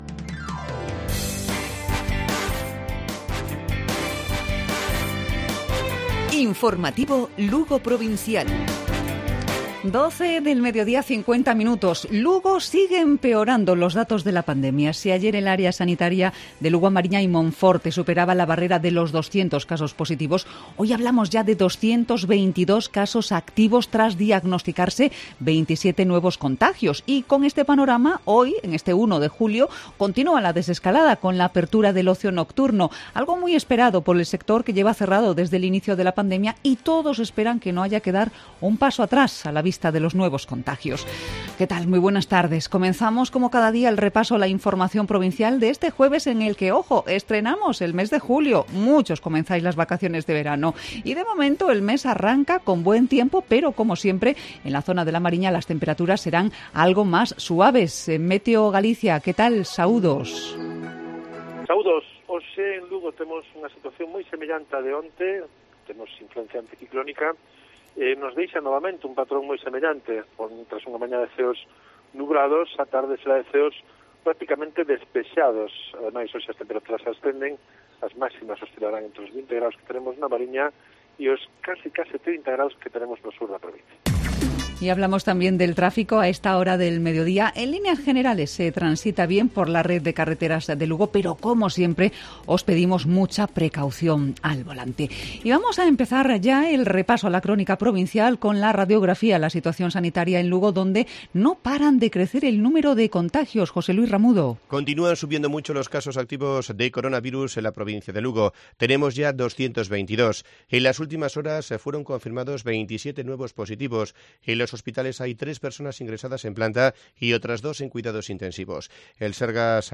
Informativo Provincial Cope Lugo. Jueves,1 de julio. 12:50 horas.